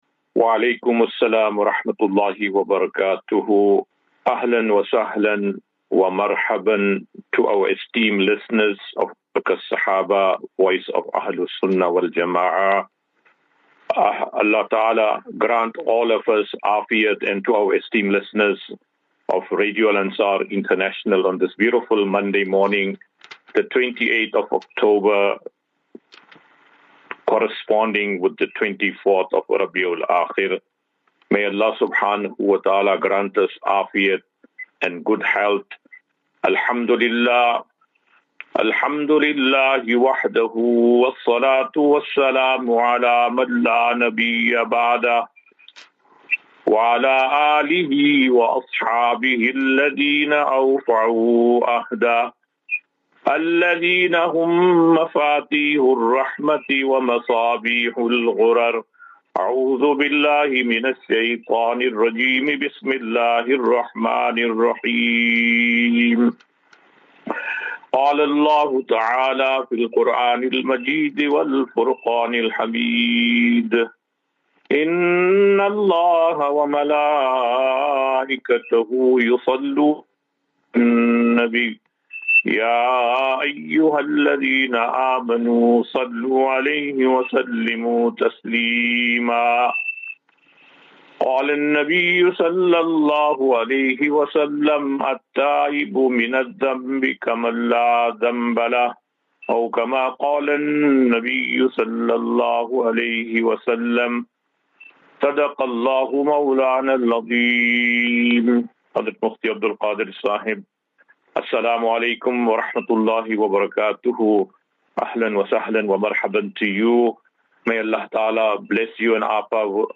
Assafinatu - Illal Jannah. QnA
Daily Naseeha.